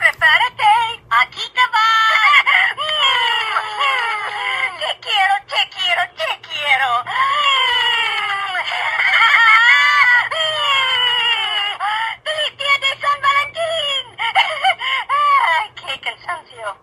Exactly What You Deserve (Titled Smooch On Hallmark's Site) is a hoops&yoyo spanish greeting card with sound made for valentine's day.